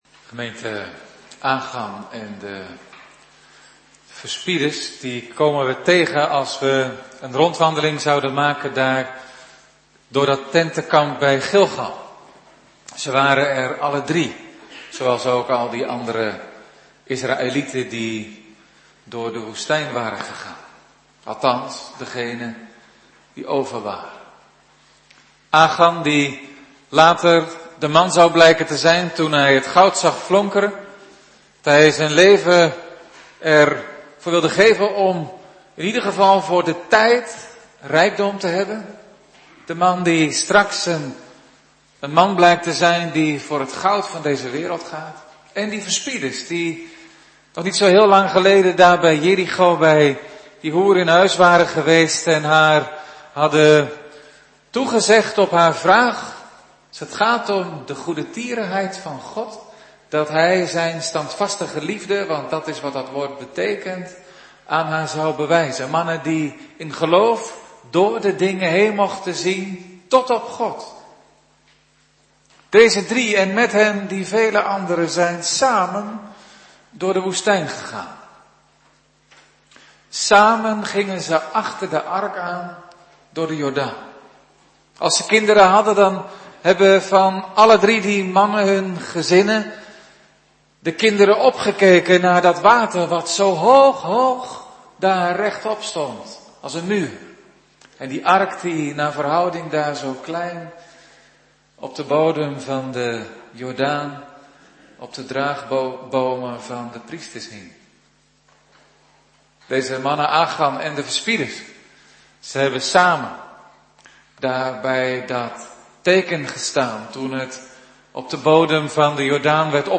Soort Dienst: Voorbereiding Heilig Avondmaal